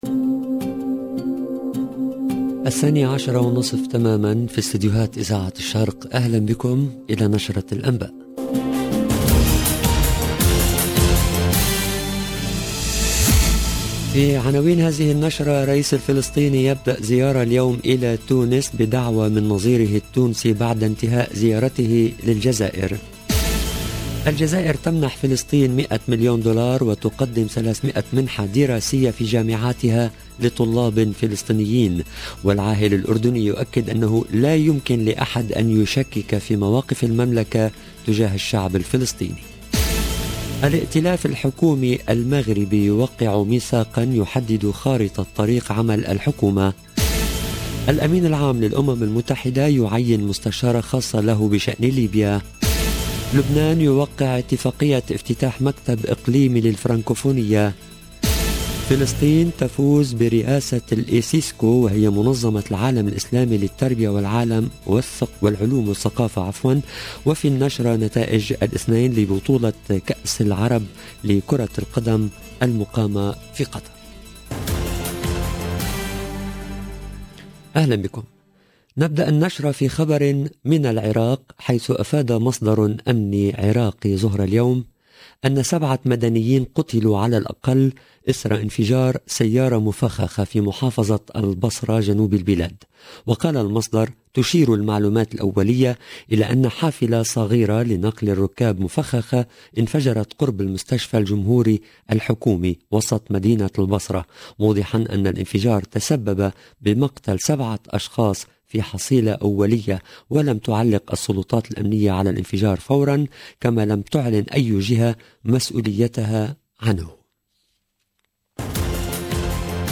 LE JOURNAL EN LANGUE ARABE DE MIDI 30 DU 7/12/21